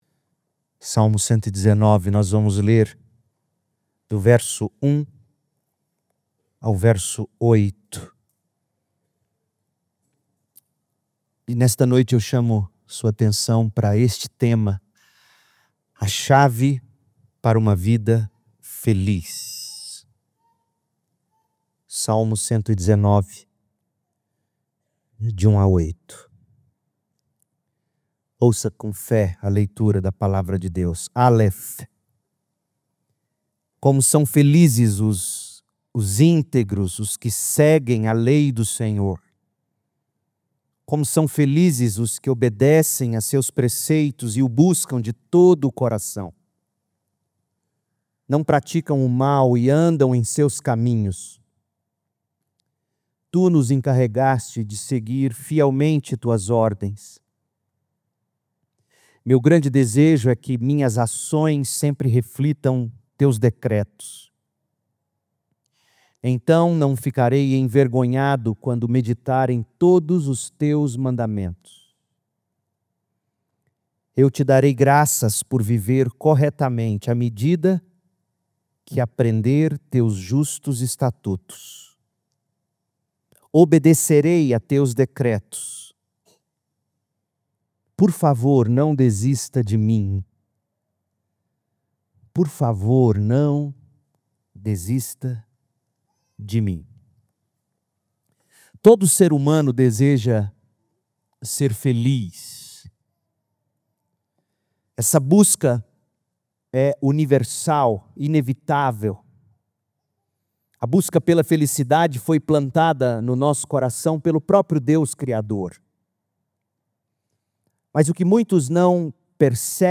A chave para uma vida feliz - Segunda Igreja Batista em Goiânia